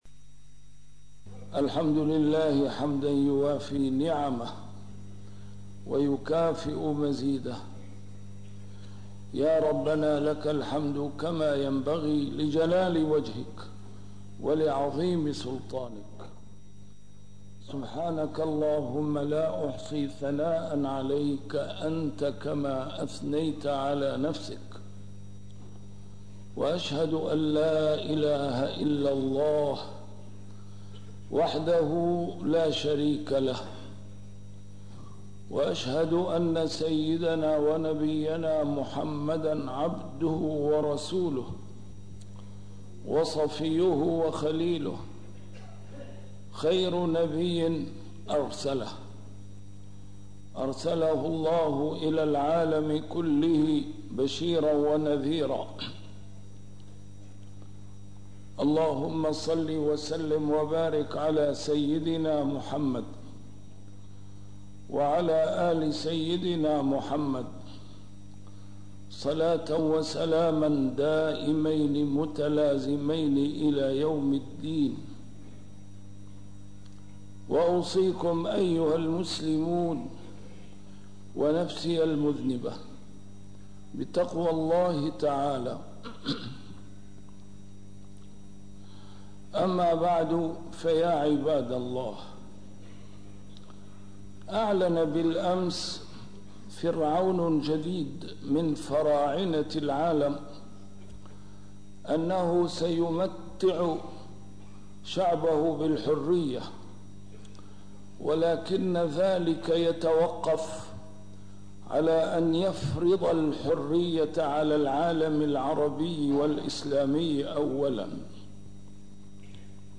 A MARTYR SCHOLAR: IMAM MUHAMMAD SAEED RAMADAN AL-BOUTI - الخطب - فرعون